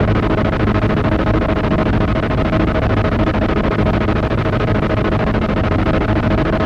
Future_engine_13_on.wav